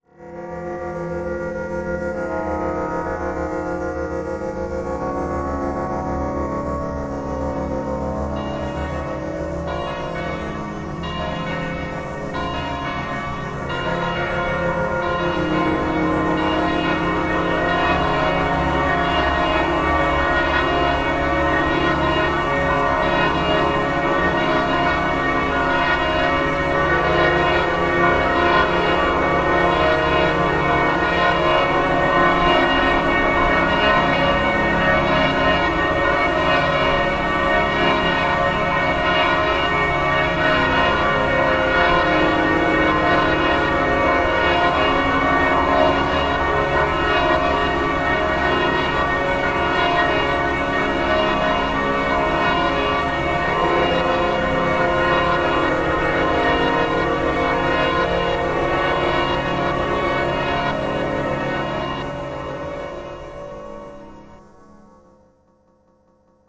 Processed version of the church bells at Auronzo di Cadore, Italy.